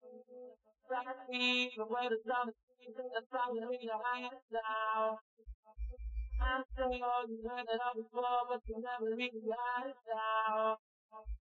Example Audio: Best SIR from Sparsity introduced in W & H